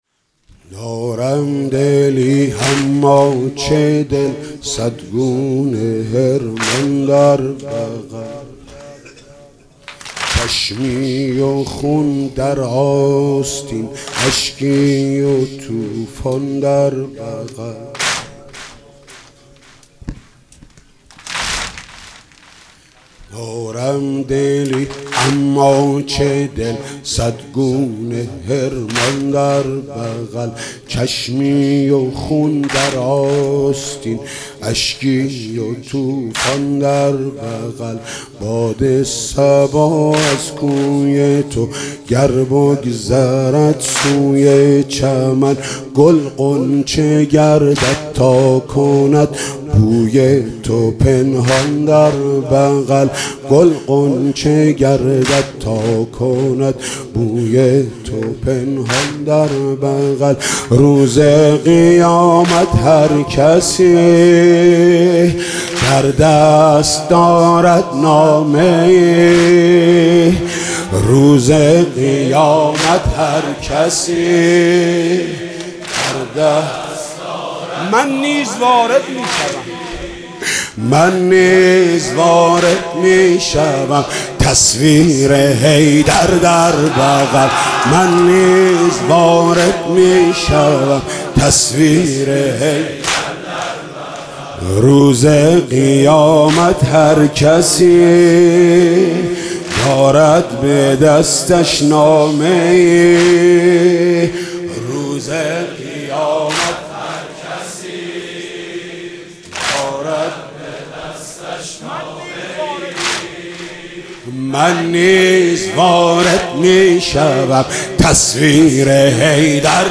گزارش صوتی شب بیست دوم ماه رمضان در هیئت رایةالعباس(ع)